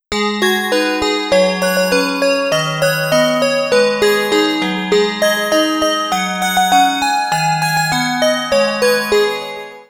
ん！せり込み蝶六をアレンジした駅メロディーを導入
魚津駅新到着メロ 将来ん流れるパタンヌ